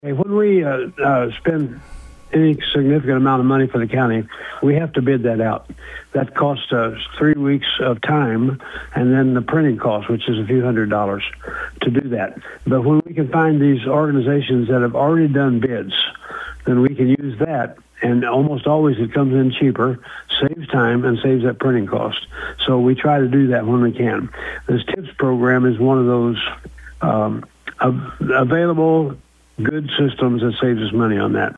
Presiding Commissioner Harold Gallaher says that partnership will help move the renovation work forward as the county continues updating the building for public use.